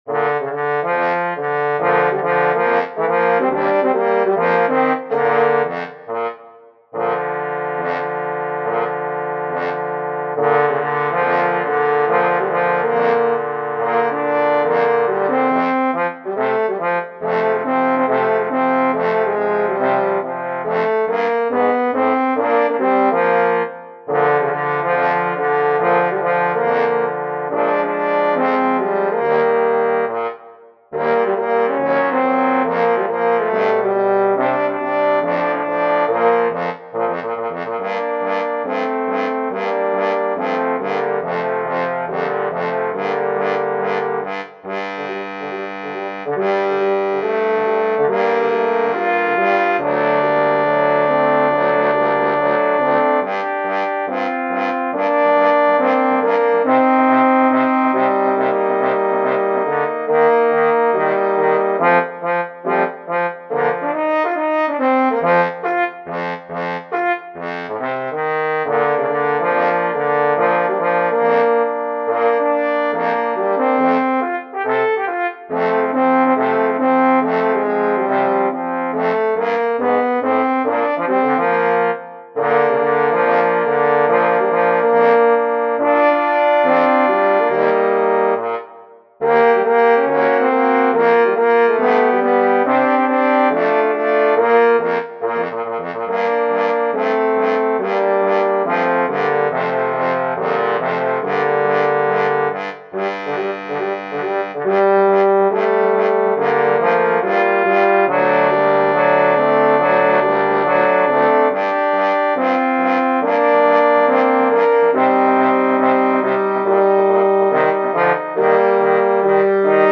musescore audio